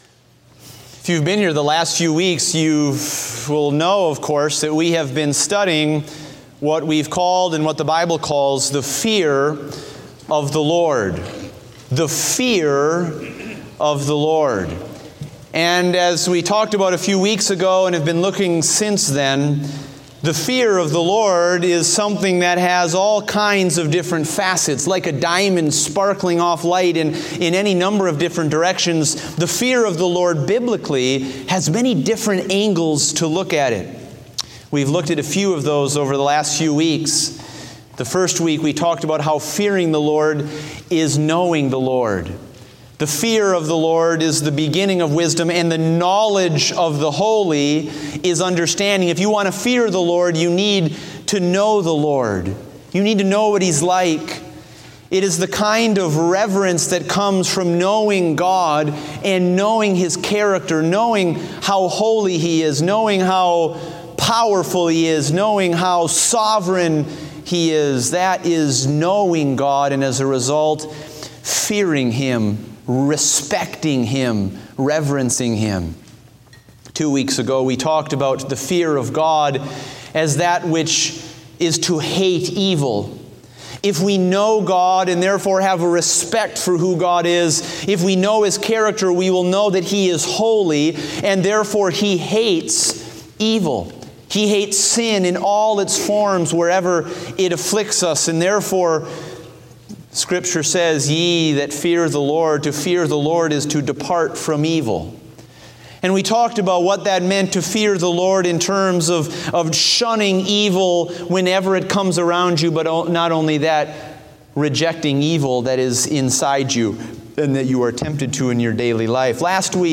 Date: November 6, 2016 (Morning Service)